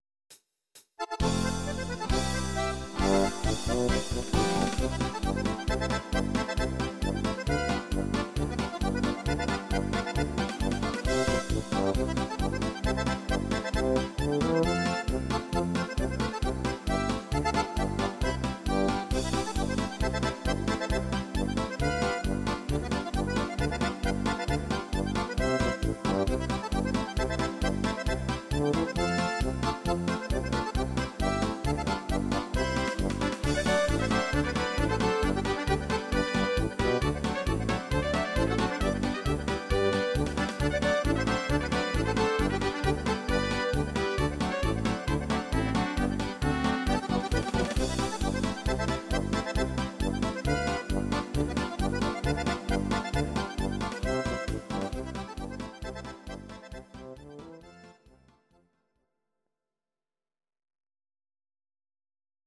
Audio Recordings based on Midi-files
Our Suggestions, Pop, German, 1990s, Volkstï¿½mlich